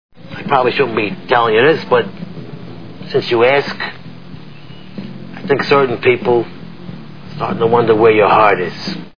The Sopranos TV Show Sound Bites